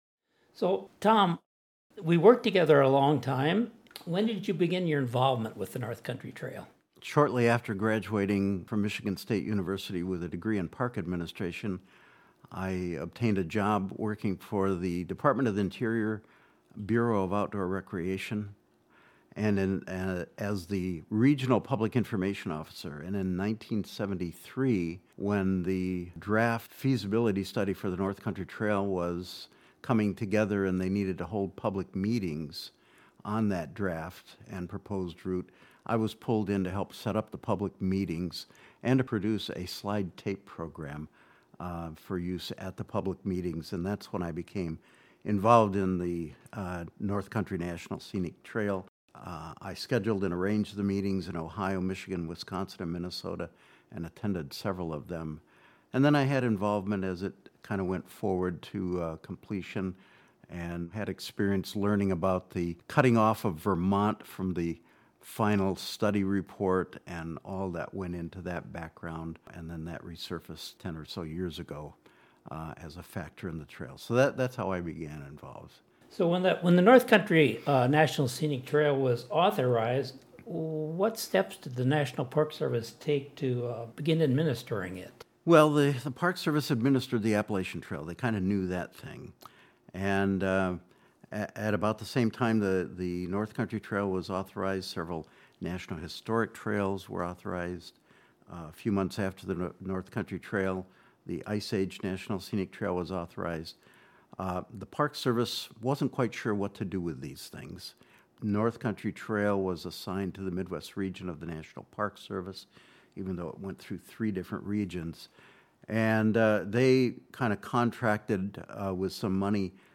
We collaborated with StoryCorps Studios in 2022 to begin capturing conversations between the remarkable people who help develop, maintain, protect, promote, and use the North Country National Scenic Trail.
The additional stories below were produced by North Country Trail Association, using interviews recorded by StoryCorps.